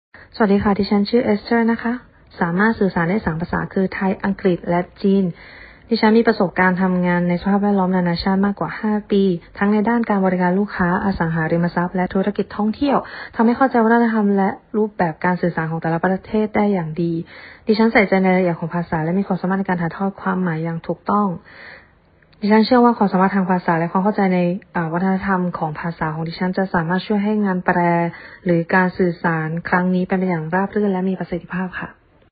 我们口译师专业自介音档试听如下，都是派出语言流利的跨国语言译者服务您：
✔泰语自介音档: